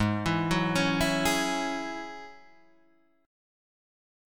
Ab7sus4#5 Chord